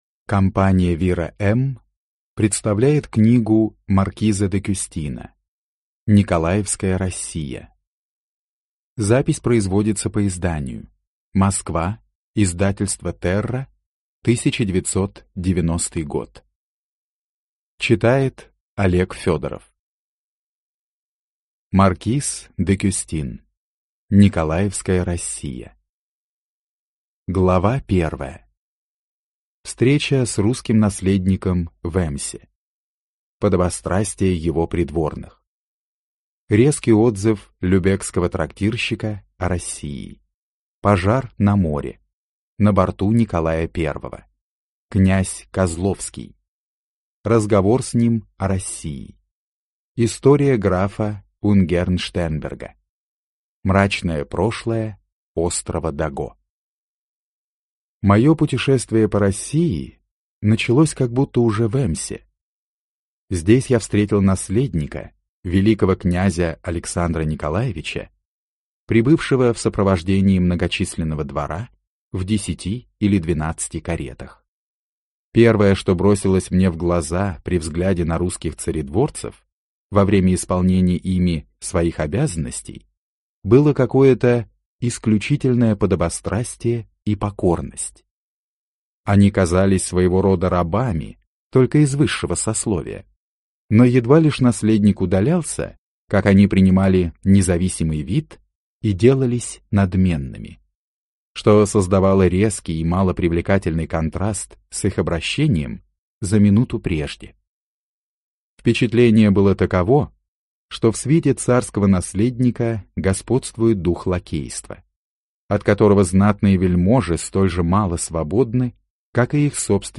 Аудиокнига Николаевская Россия | Библиотека аудиокниг